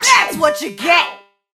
jackie_drill_kill_vo_02.ogg